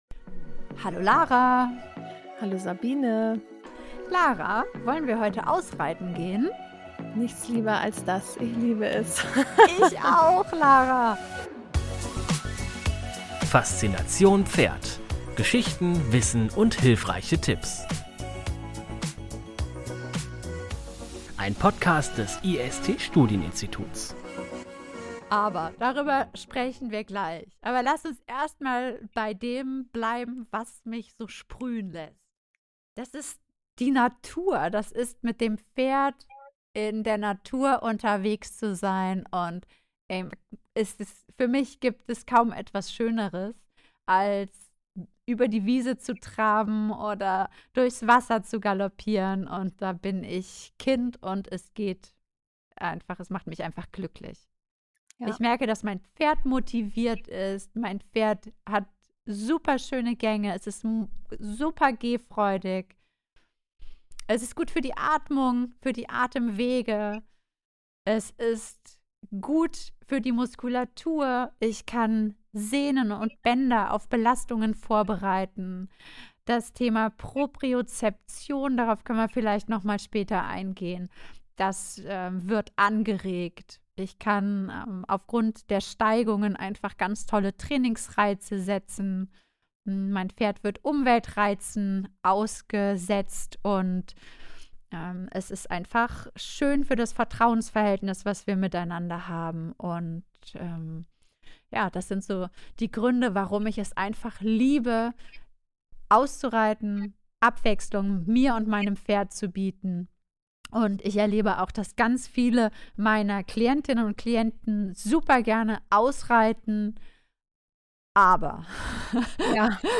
Entdecke, warum Gelassenheitstraining und das Führen an der Hand unerlässlich sind, um Dein Pferd auf verschiedene Umwelteinflüsse vorzubereiten. Die beiden Pferderverhaltenstrainerinnen sprechen außerdem über Sicherheitsaspekte, das richtige Equipment und die Bedeutung der Körperlichkeit sowohl für Menschen als auch für Pferde.